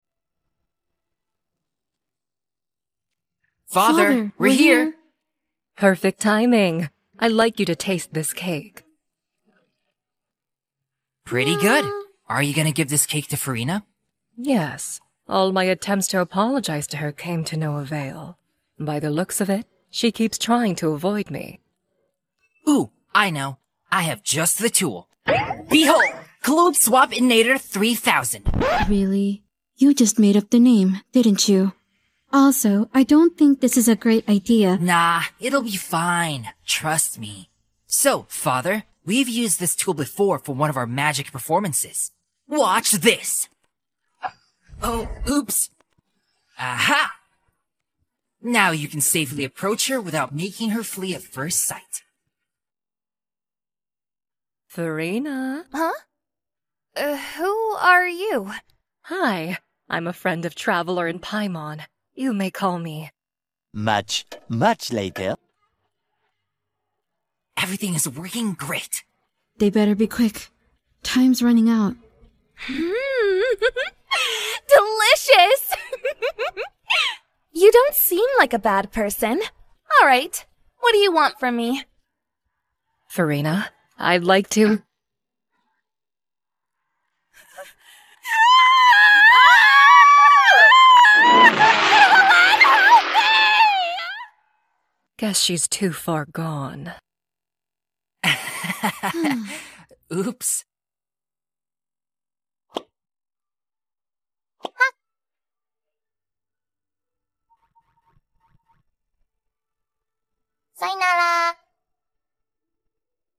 Voz humana